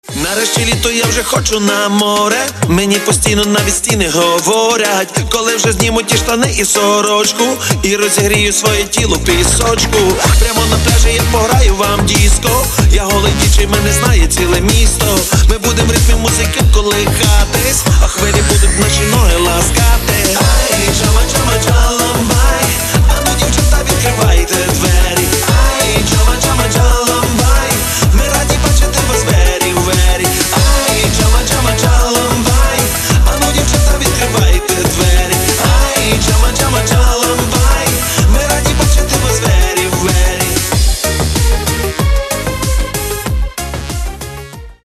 Каталог -> Поп (Легкая) -> Ремиксы